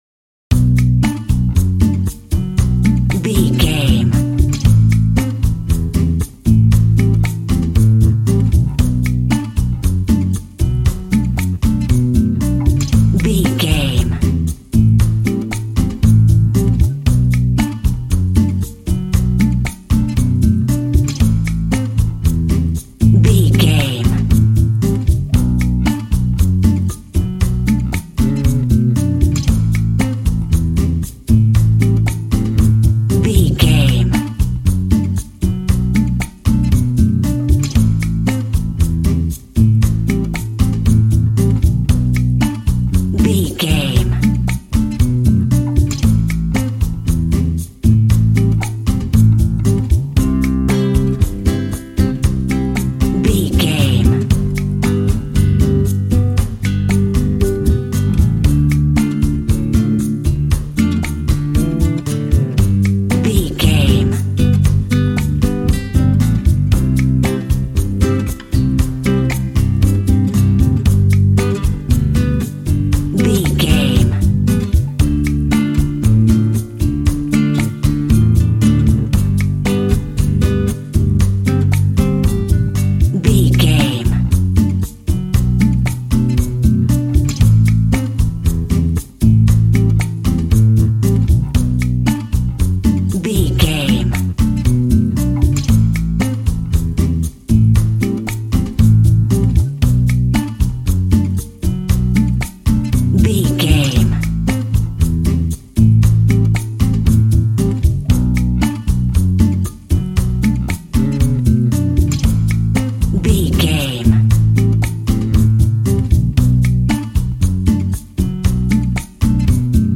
Ionian/Major
funky
energetic
percussion
electric guitar
acoustic guitar